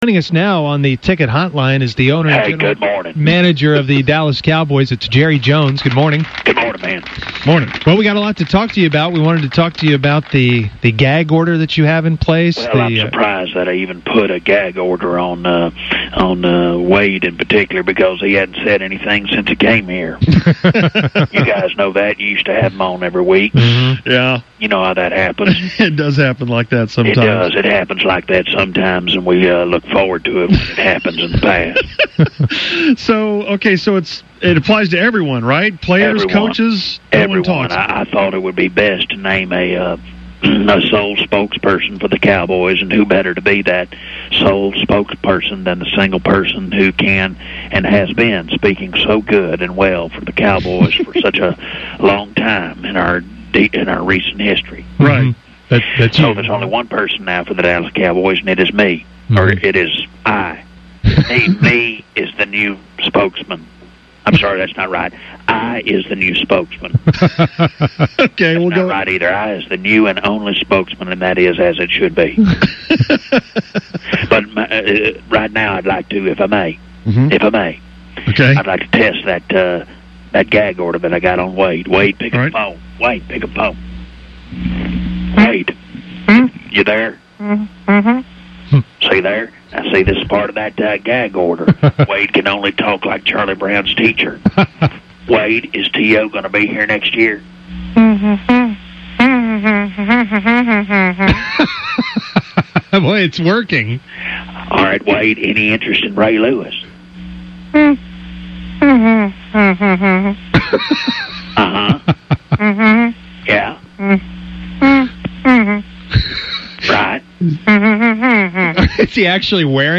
Fake Jerry was on with the Musers this morning, with a very short visit from a gagged fake Wade.
Wade was asked questions about football and he spoke like a Charlie Brown character. Well, until he asked him about foot which he removed the gag and spoke.
fake-jerry-wade-gagged.mp3